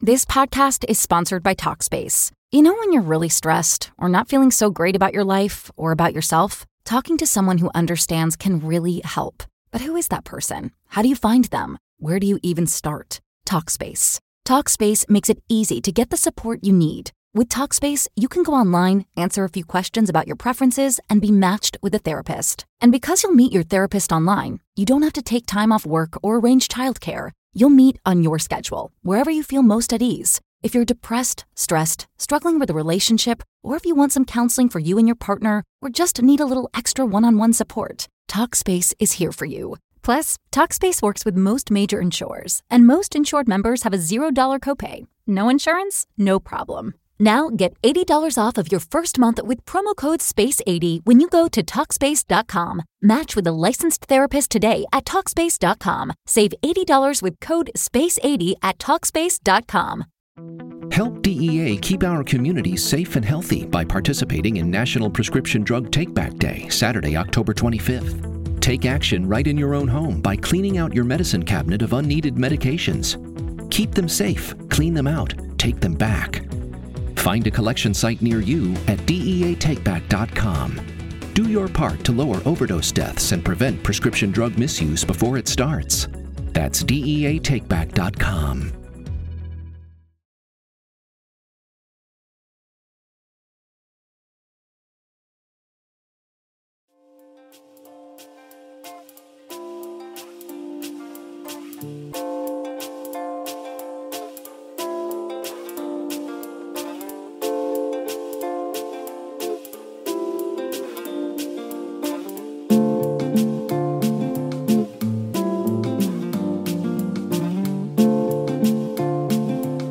Welcome to the live Q&A, where it is never a dull moment!